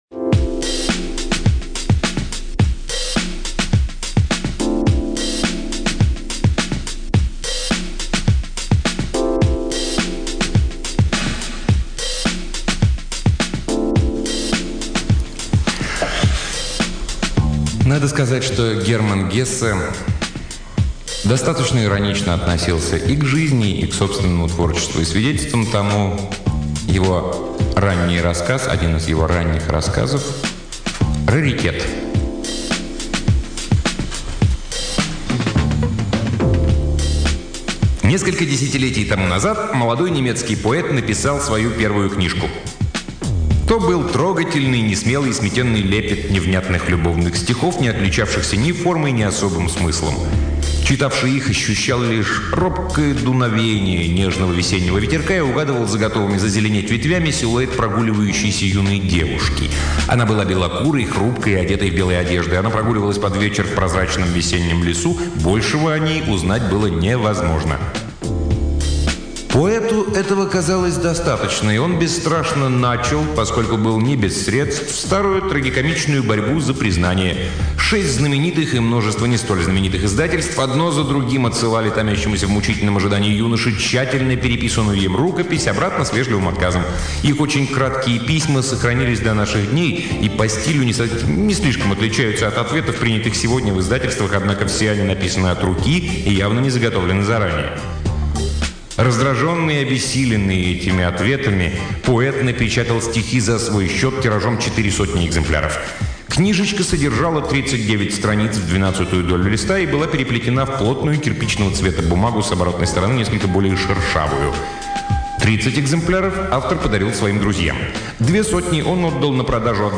Аудиокнига Герман Гессе — Раритет
Fila Brazillia — Space Hearse Fila Brazillia — Little Dipper